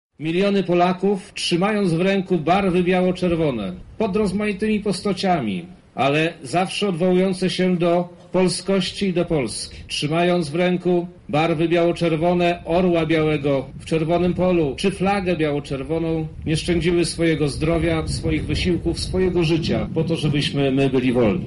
Także na samym placu Litewskim odbyło się uroczyste podniesienie.
W trakcie uroczystości do zebranych zwrócił się wojewoda Przemysław Czarnek: